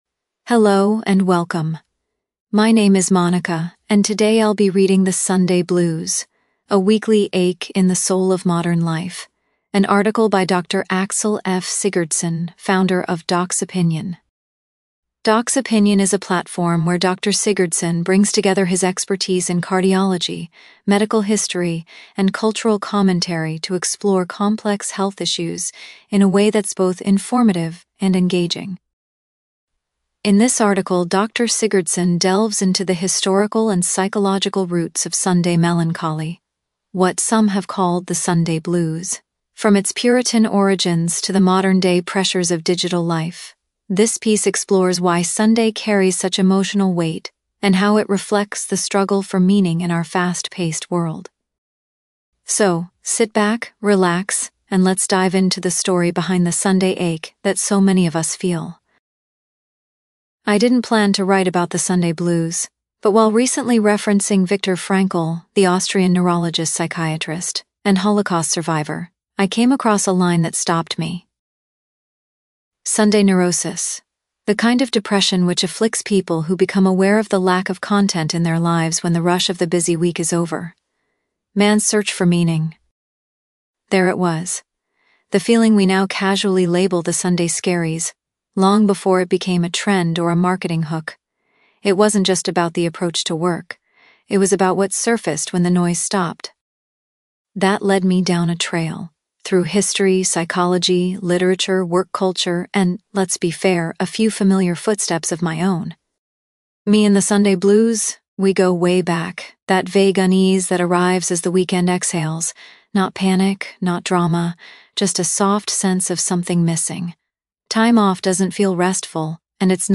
Share this post: Share on X (Twitter) Share on Facebook Share on LinkedIn Share on Email Estimated reading time: 16 minutes 🎧 Now available in audio You can listen to this article — The Sunday Blues: A Weekly Ache in the Soul of Modern Life — narrated in full.